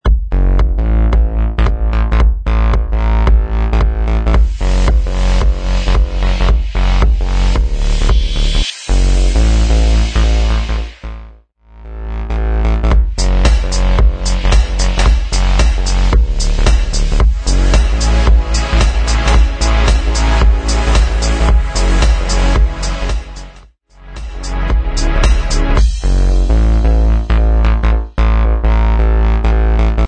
112 BPM
Electronic